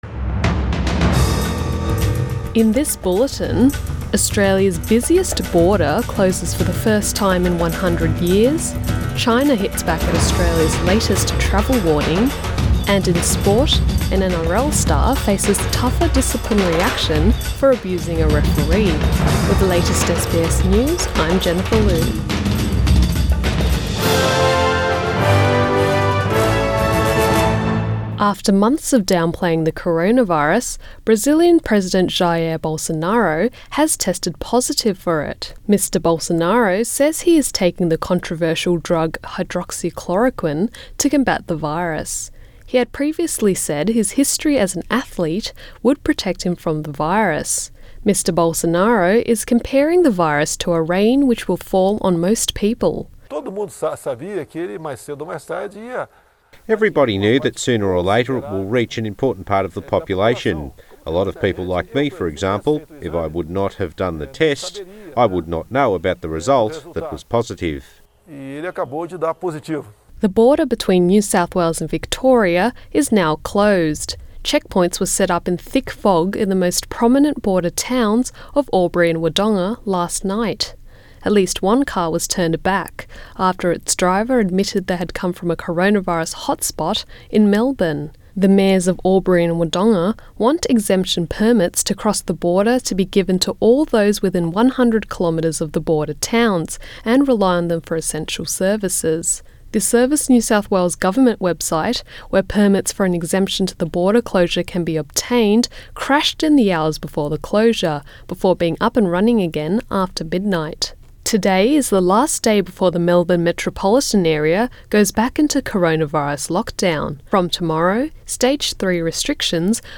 AM bulletin 8 July 2020